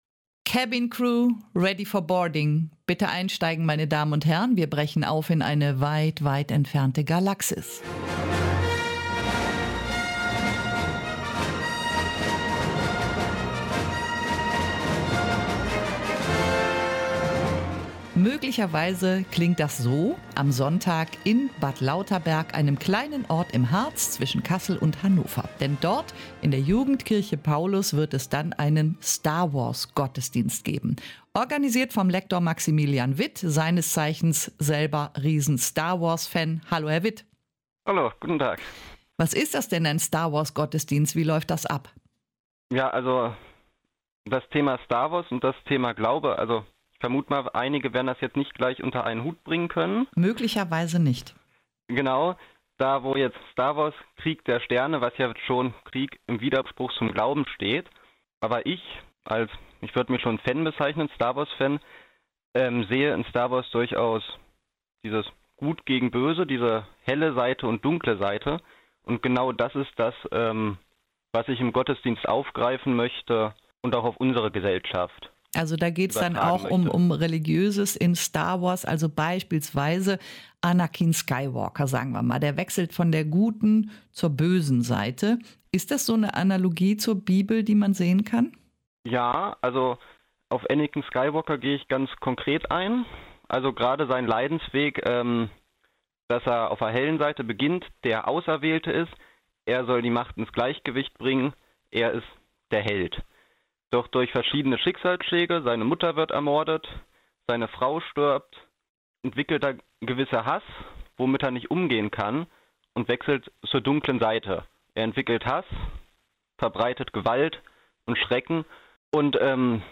In der Jugendkirche Paulus in Bad Lauterberg, einem kleinen Ort im Harz, wird es am Samstag keinen gewöhnlich Gottesdienst geben, sondern ein Gottesdienst in einer anderen Galaxie, ein St